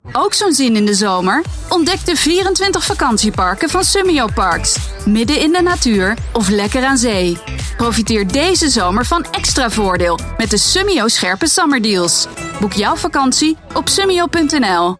Op zoek naar een vrouwelijke voice-over met energie en overtuiging?
Summio Parcs commercial